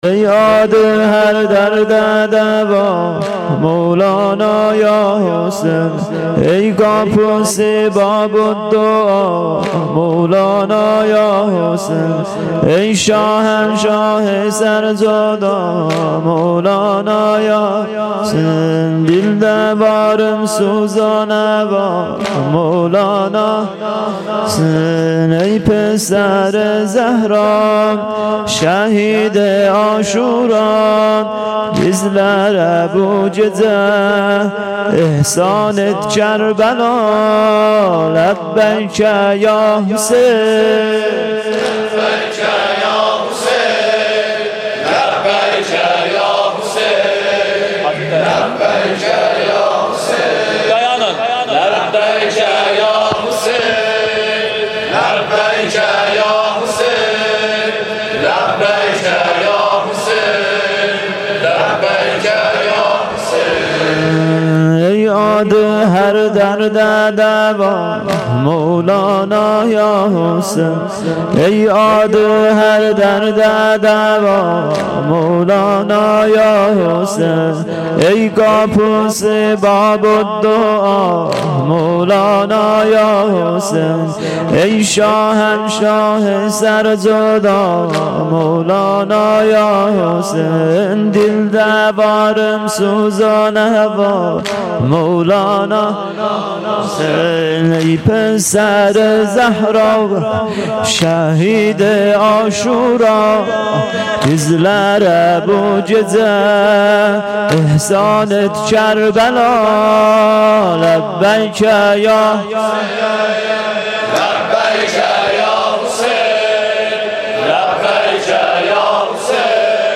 شام غریبان- بخش دوم سینه زنی